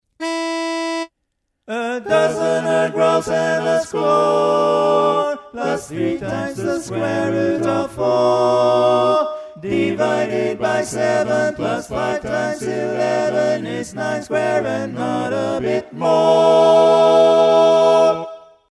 Key written in: E Major
Type: Barbershop